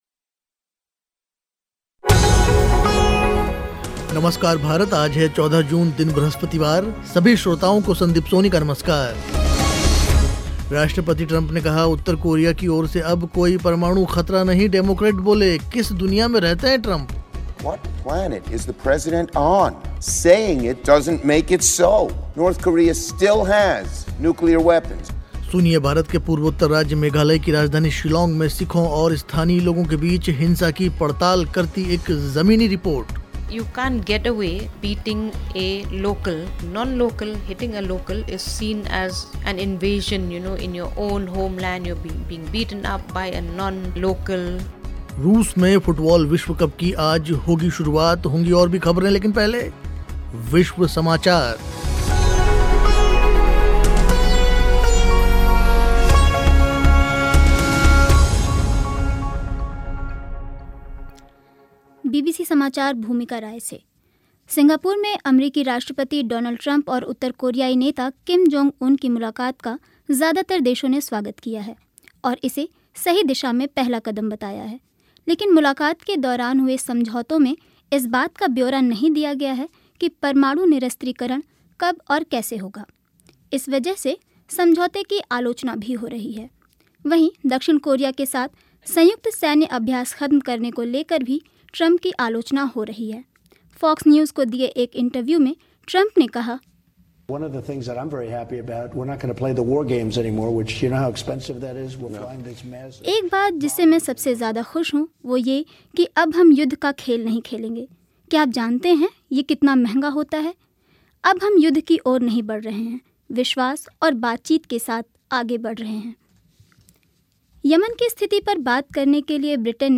सुनिए भारत के पूर्वोत्तर राज्य मेघालय की राजधानी शिलौंग में सिखों और स्थानीय लोगों के बीच हिंसा की पड़ताल करती एक ज़मीनी रिपोर्ट.